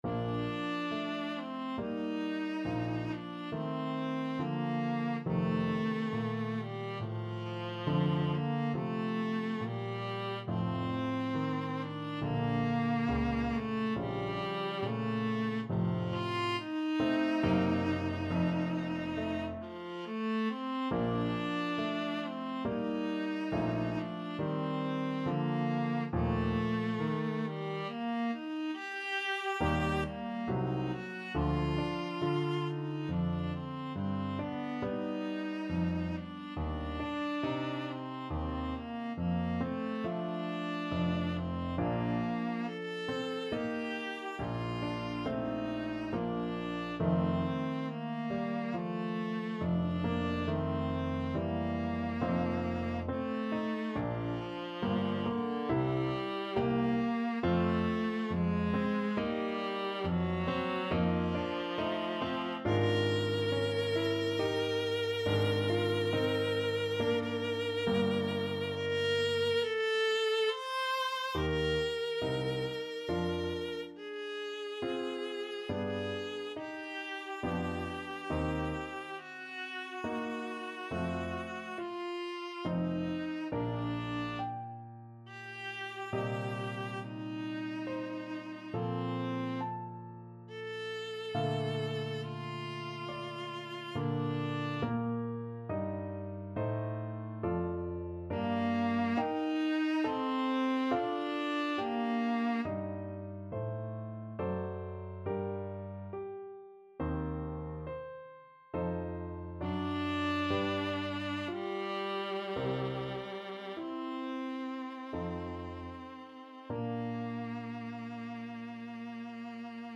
D4-C6
6/4 (View more 6/4 Music)
Andante =c.84 =69
Classical (View more Classical Viola Music)